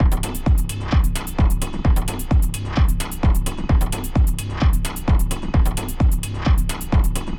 Had a play around this morning with this resampling approach for half an hour. Finally got a loop that isn’t fantastic but isn’t completely turd either.